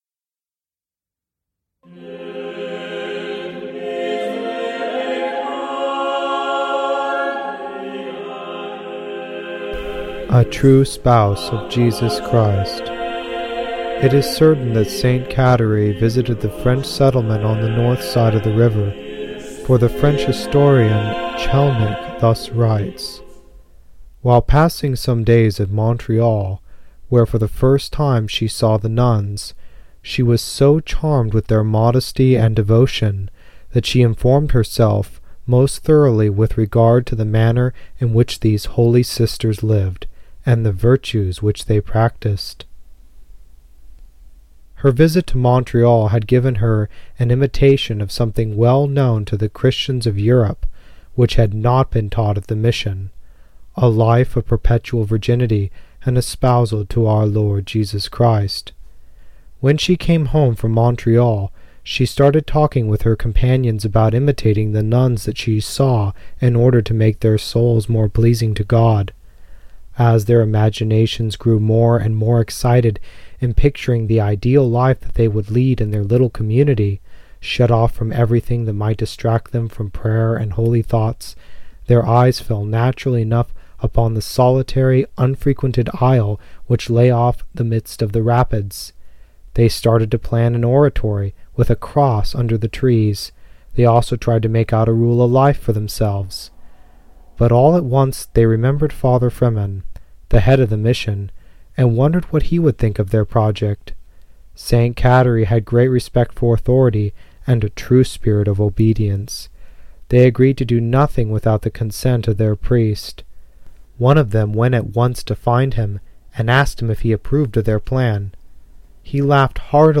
Music Public Domain (Edited)